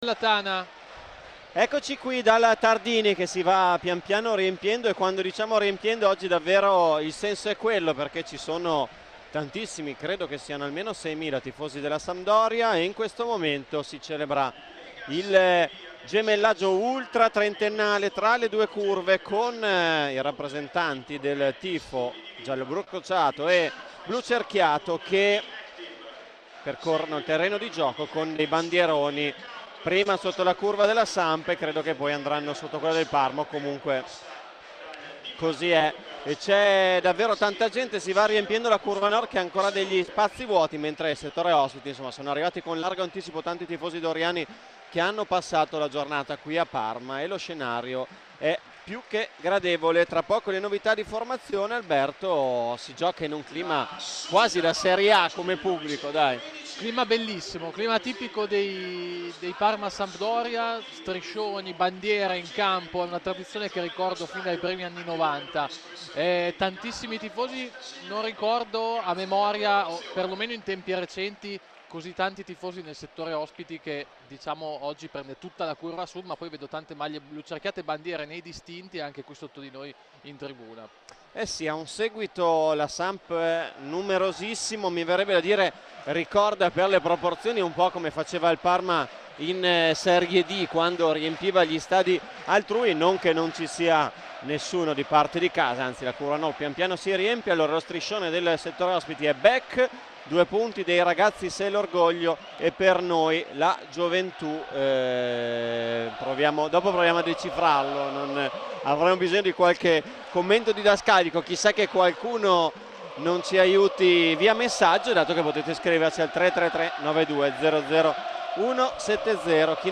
Radiocronache Parma Calcio Parma - Sampdoria - 1° tempo - 24 settembre 2023 Sep 24 2023 | 01:01:15 1x 00:00 / 01:01:15 Subscribe Share RSS Feed Share Link Embed